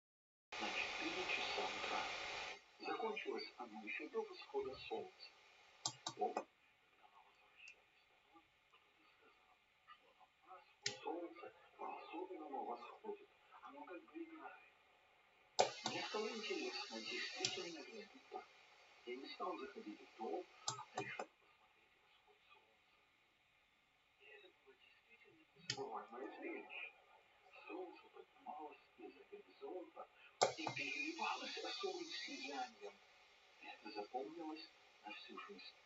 Это запись на ноутбук, другого способа у меня нет.
В этой записи переключение через 5 сек., и понятное дело, что свои записи я слушал.
В моём три в одном, наушники, микрофон и линейный, как есть, так и пользуем.